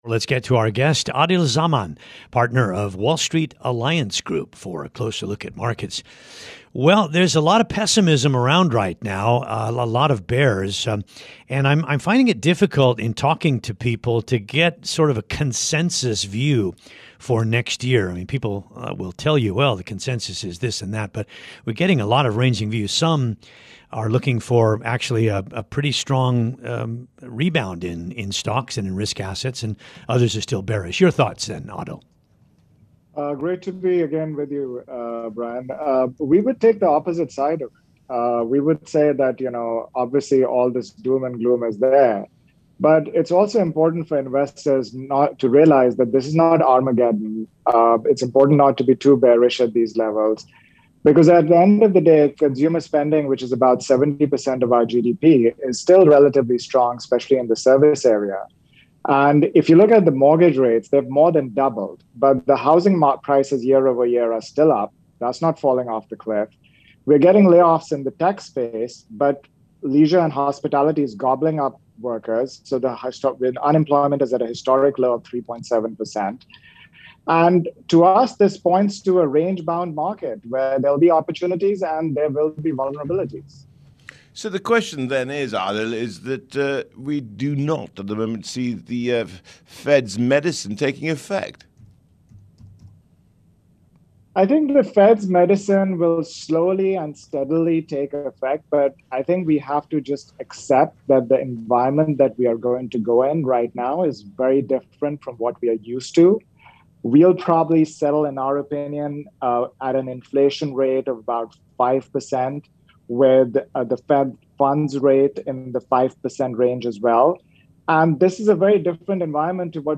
(Radio) - Bloomberg Daybreak: Asia Edition